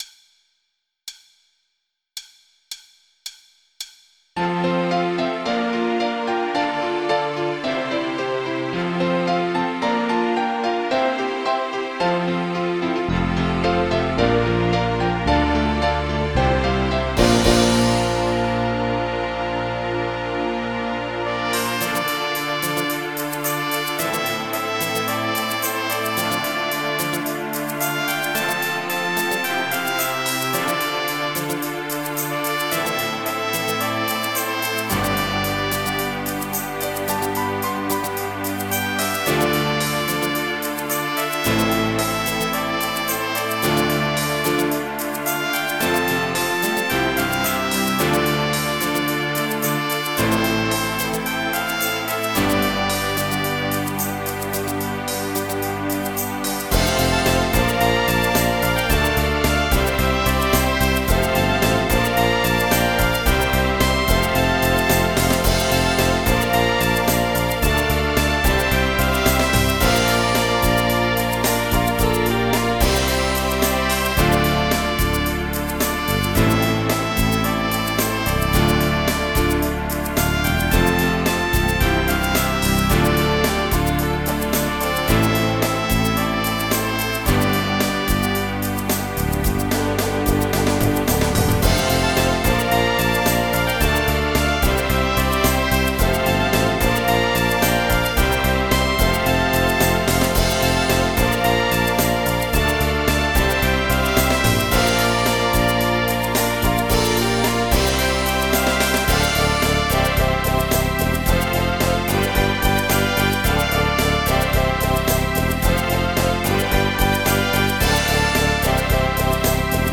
Pop
MIDI Music File